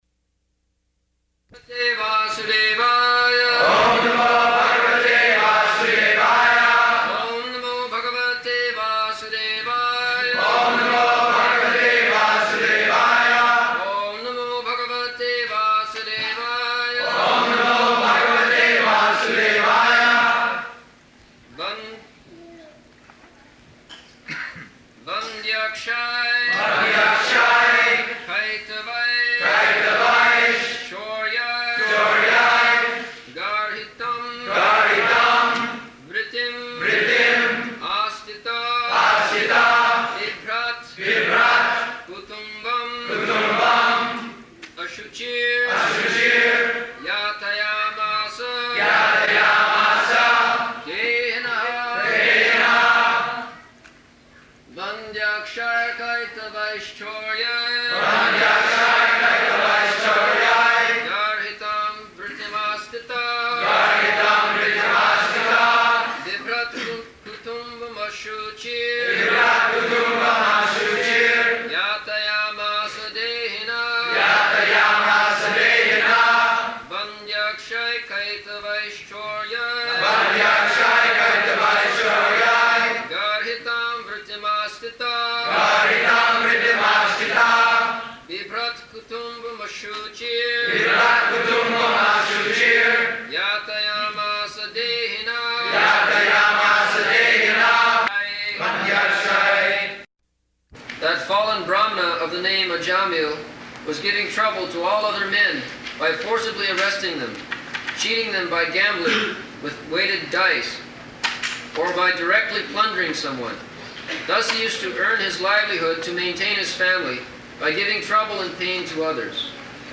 July 6th 1975 Location: Chicago Audio file
[devotees repeat] [leads chanting of verse, etc.]